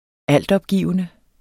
Udtale [ -ˌʌbˌgiˀvənə ]